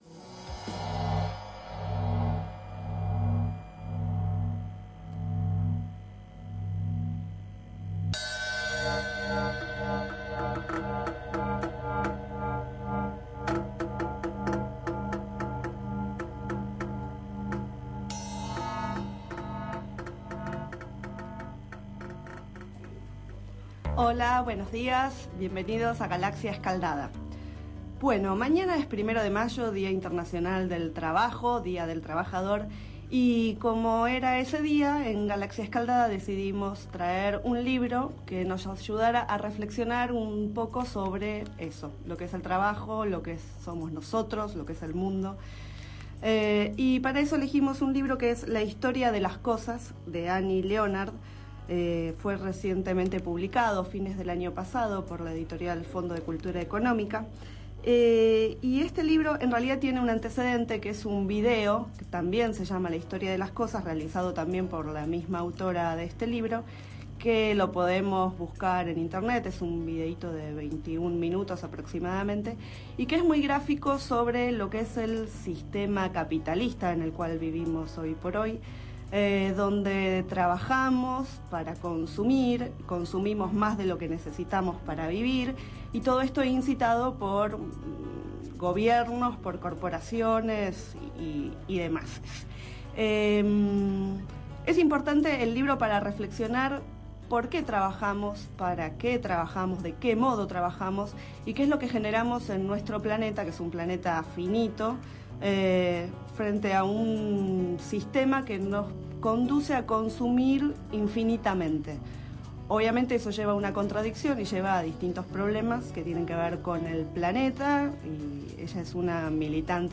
9º micro radial, realizado el 30 de abril de 2011, sobre el libro La historia de las cosas, de Annie Leonard.
Este es el 9º micro radial, emitido en el programa Enredados, de la Red de Cultura de Boedo, por FMBoedo, realizado el 30 de abril de 2011, sobre el libro La historia de las cosas, de Annie Leonard.